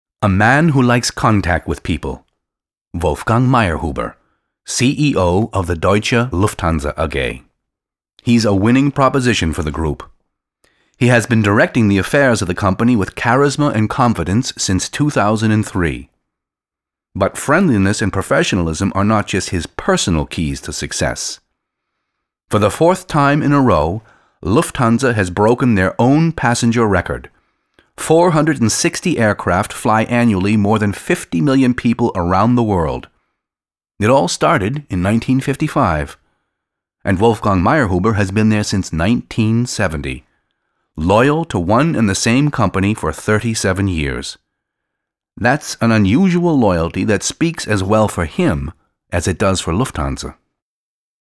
mid-atlantic
Sprechprobe: Industrie (Muttersprache):
english (us) voice over for industry films, image films, movies, and computer games.